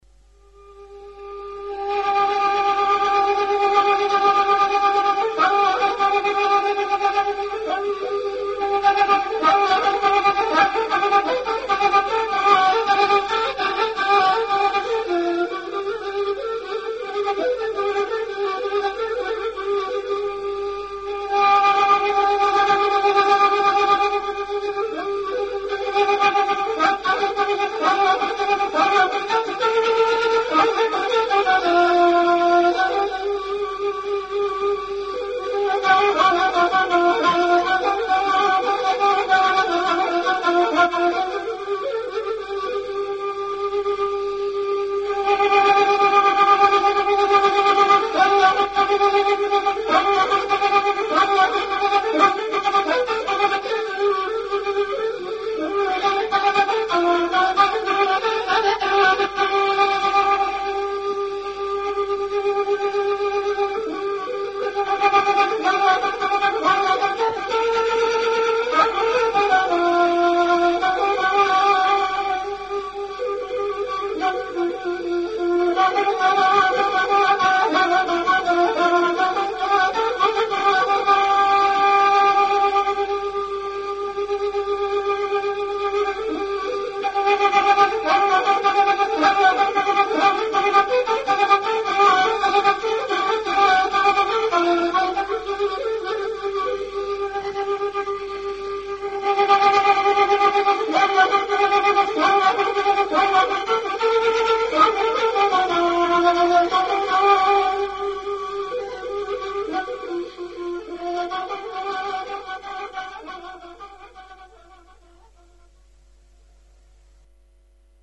شمشال
این ساز از لحاظ نوع صدا و وسعت در زمره سازهای بم با طنین خاص خود است و بیشتر مورد استفاده در حوزه درویشان است.
شمشال کردستان